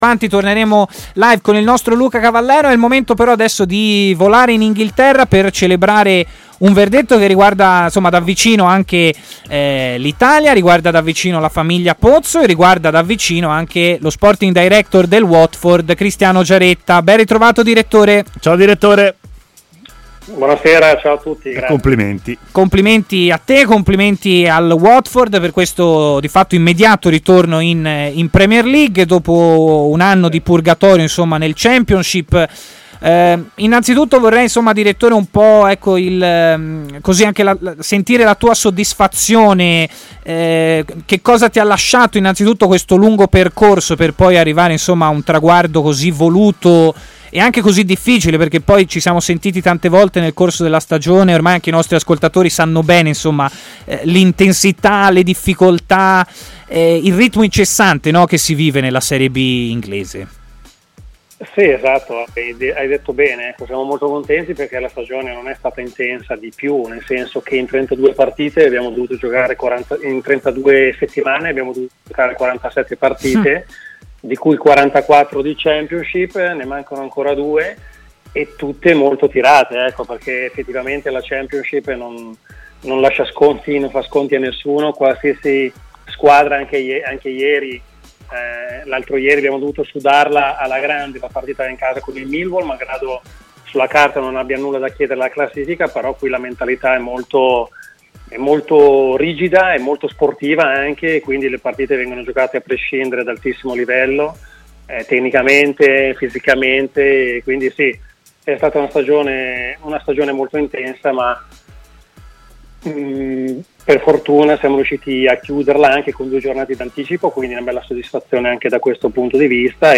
ha parlato in diretta a TMW Radio, nel corso della trasmissione Stadio Aperto.